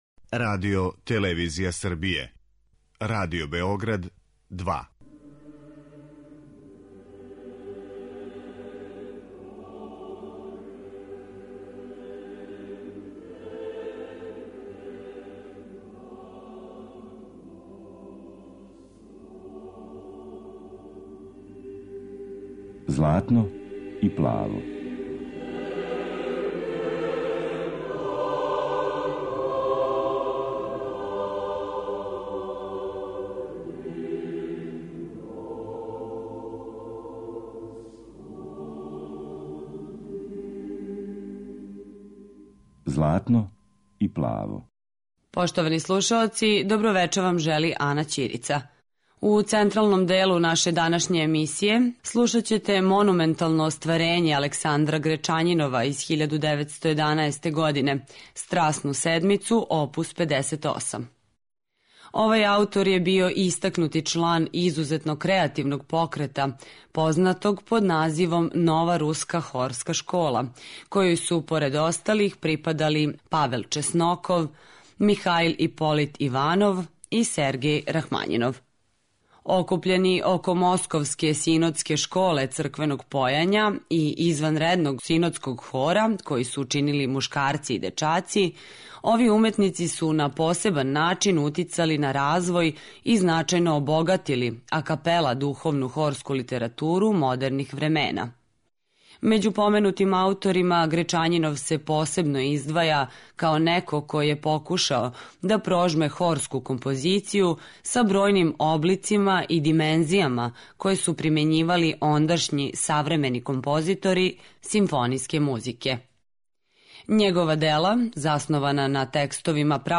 Вечарашње издање емисије православне духовне музике посвећено је музици Александра Гречањинова.